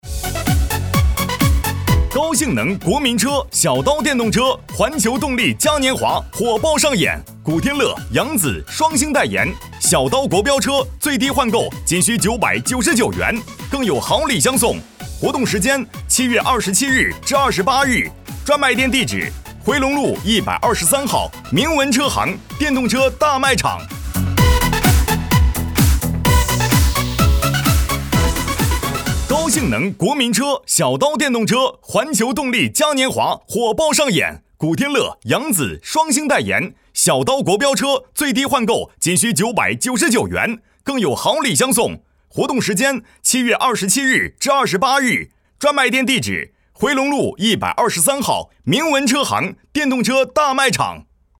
男33号配音师
促销-男33-电动车+干音.mp3